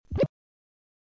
clickother.mp3